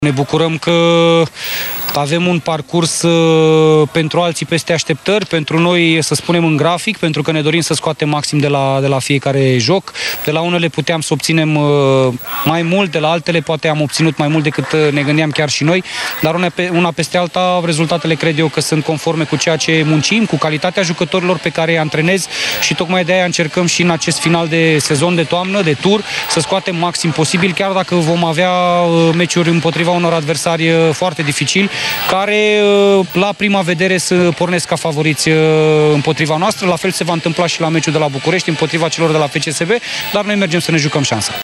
La finele meciului de Cupa României, de la Miercurea Ciuc, tehnicianul utist a spus că prezentul îl leagă de clubul alb-roșu, pe care și-l dorește cu o mai mare stabilitate.